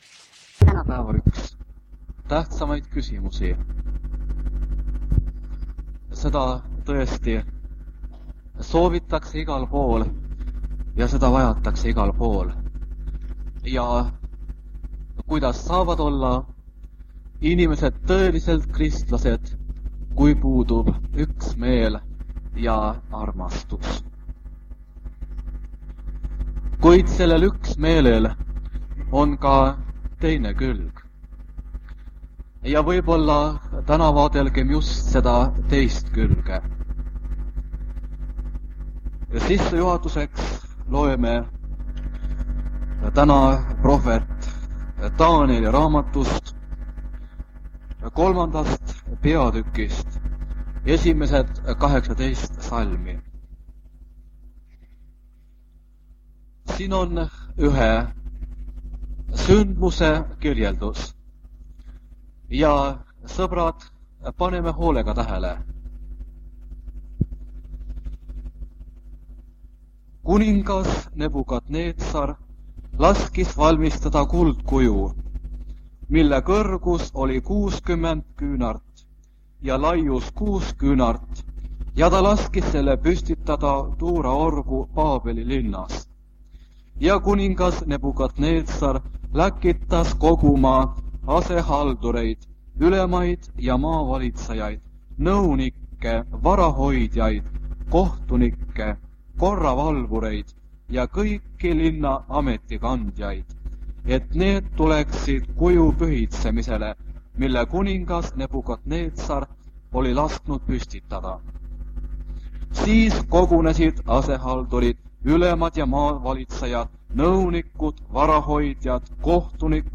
Jutlus vanalt lintmaki lindilt 1978 aastast.
On ka kaks osalist lauluettekannet.